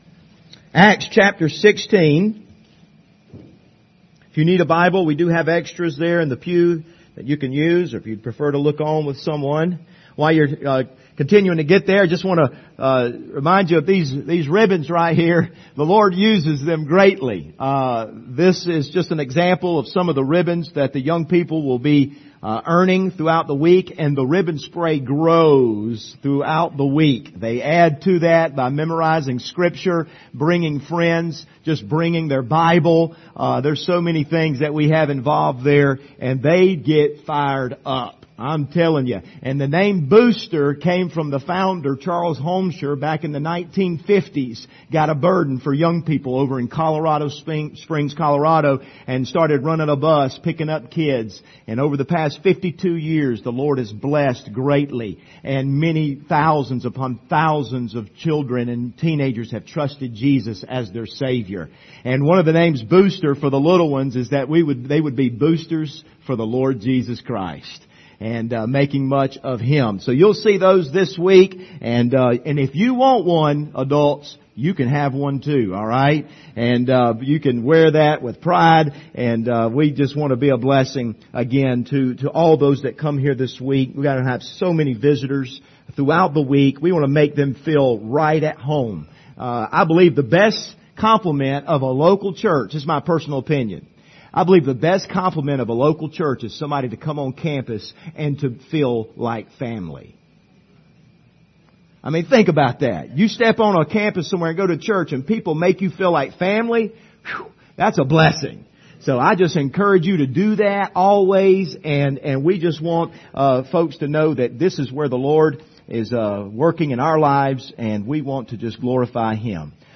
Passage: Acts 16:26-31 Service Type: Sunday Morning